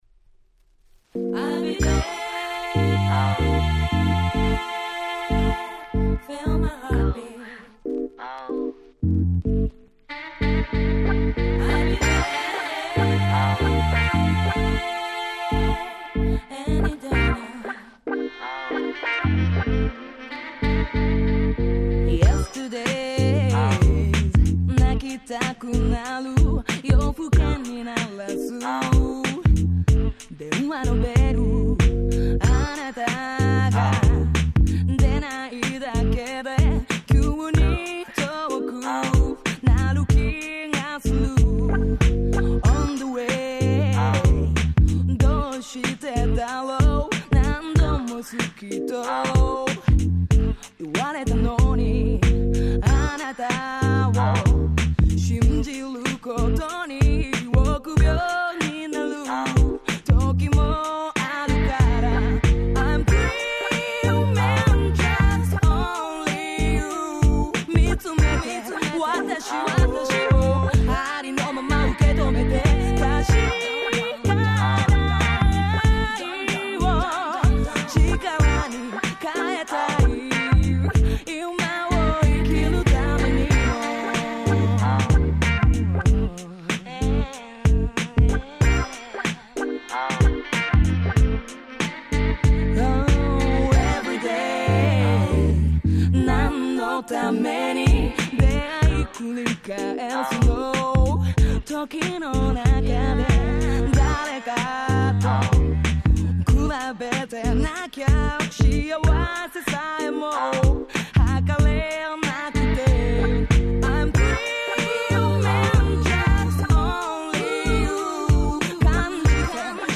99' Nice Japanese R&B !!